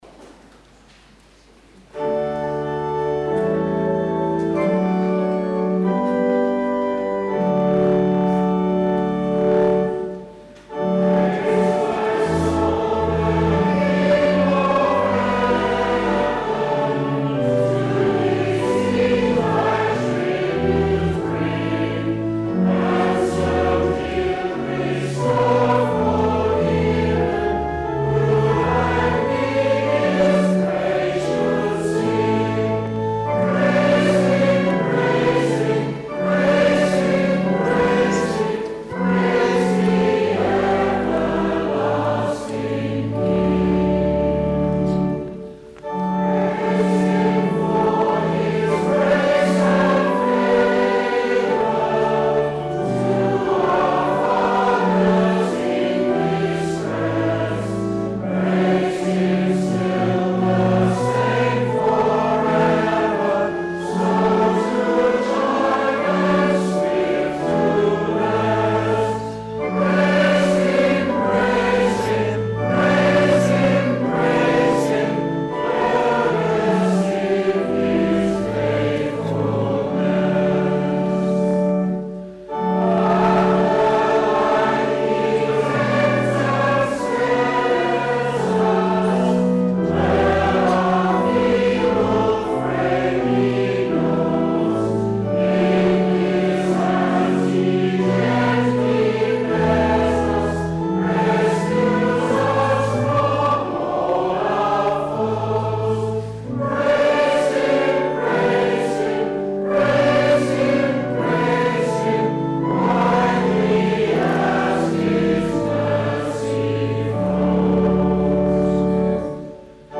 Induction Service
Click below to hear the full Induction Service